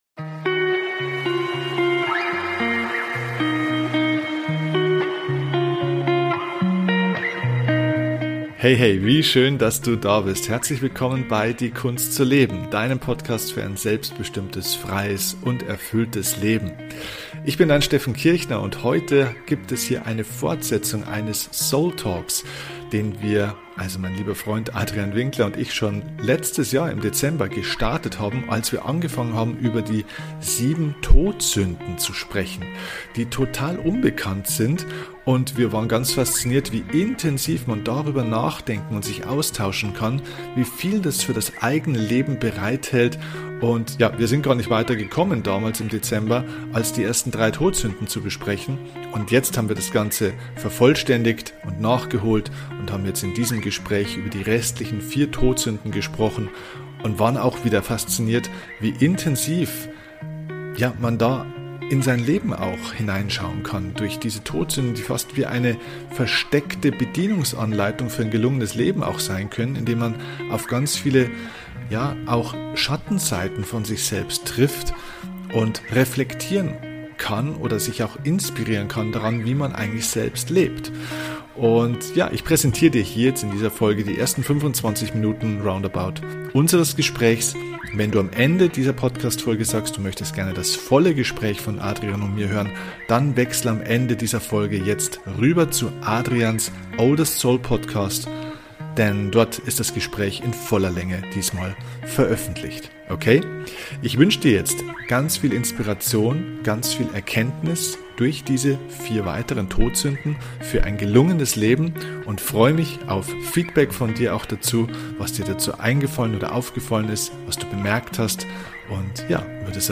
Heute kommt die Fortsetzung. Wie immer beim SOULTALK gibt es kein Skript!